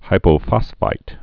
(hīpō-fŏsfīt)